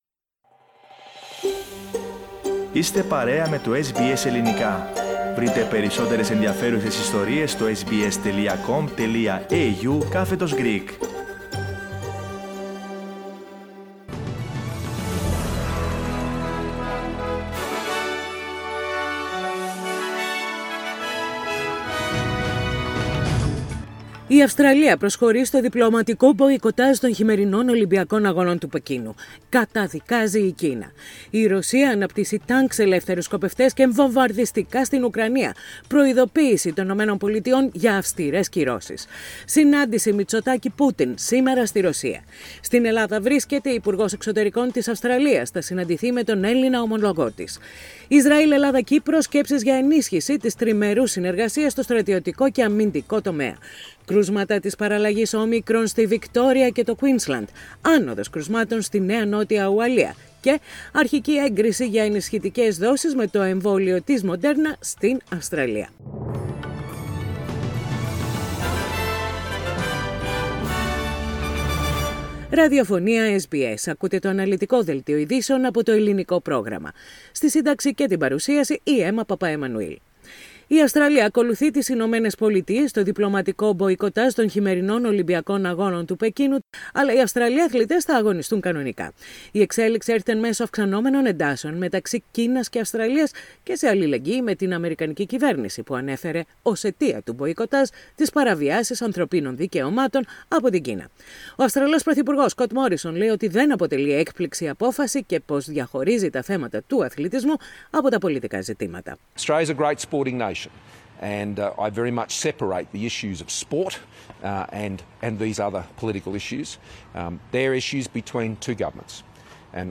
The detailed news bulletin of the day, with the main news from Australia, Greece, Cyprus and the international arena.